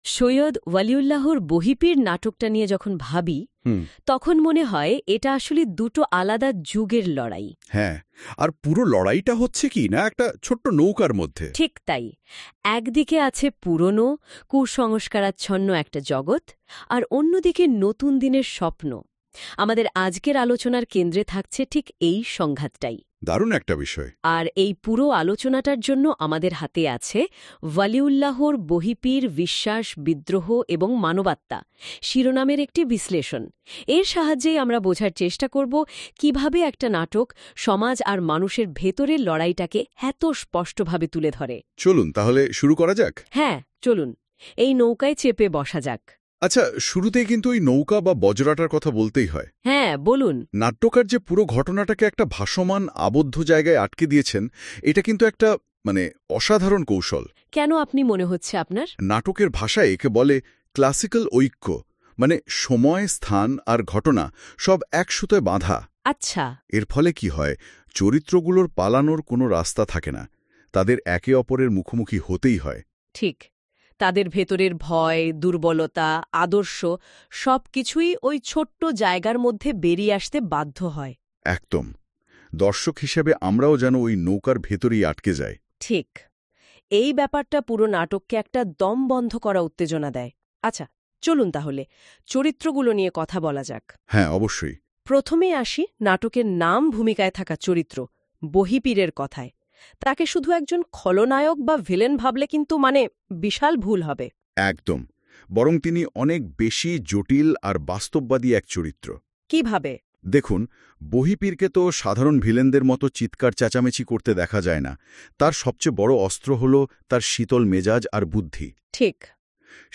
Drama.mp3